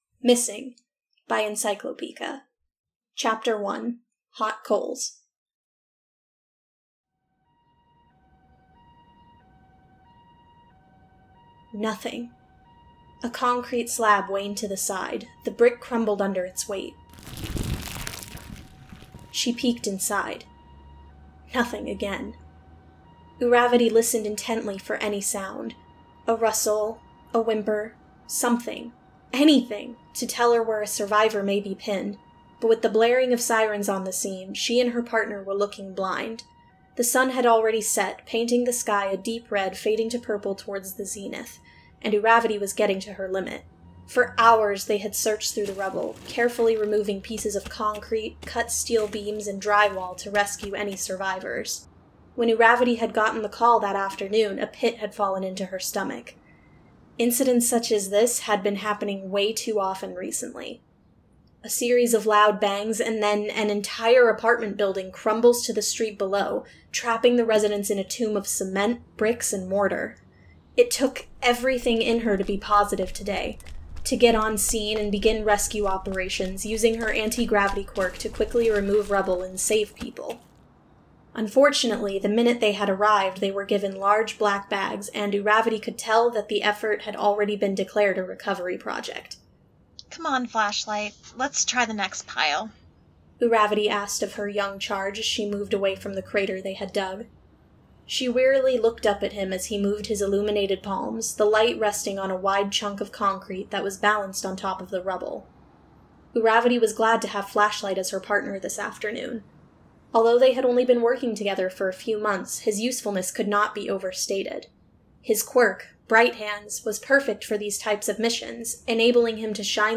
Voice of Zombies [OC]